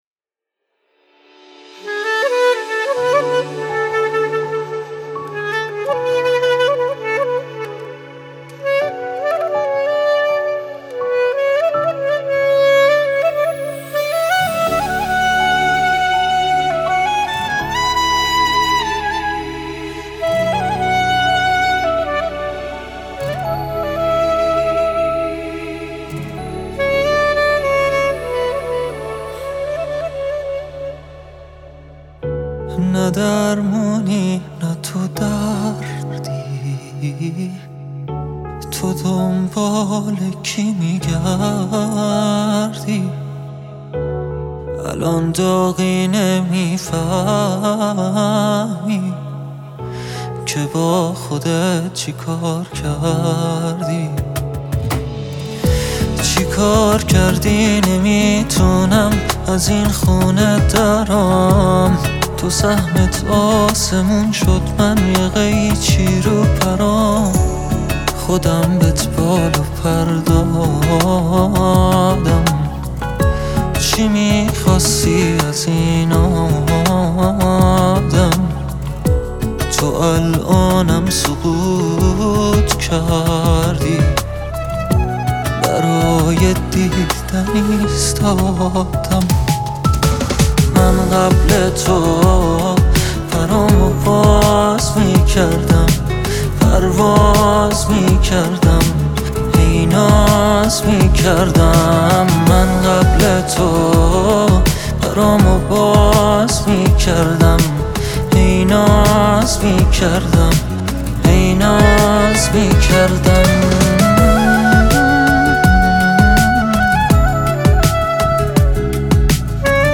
پاپ غمگین عاشقانه عاشقانه غمگین